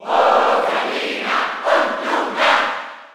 Category:Crowd cheers (SSB4) You cannot overwrite this file.
Rosalina_&_Luma_Cheer_German_SSB4.ogg